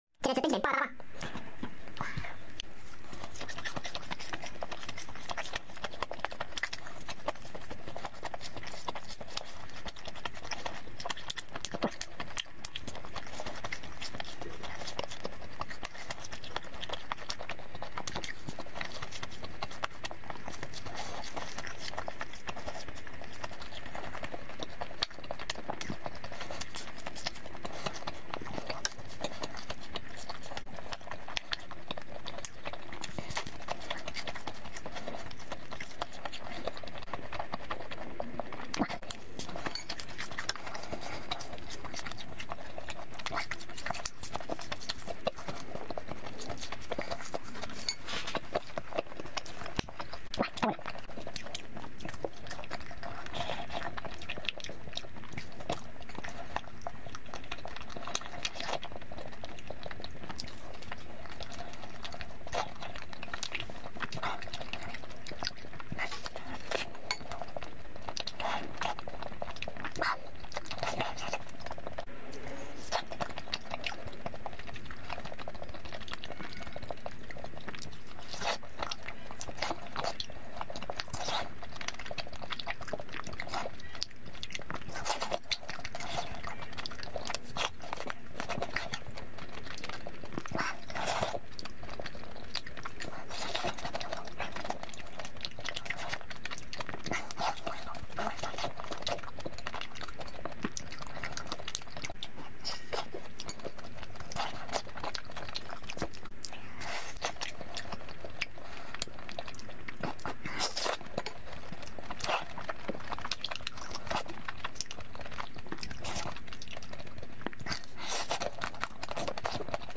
Upload By ASMR EATING
Eating Ice-cream It's so addictive!